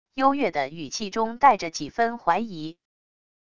幽月的语气中带着几分怀疑wav音频生成系统WAV Audio Player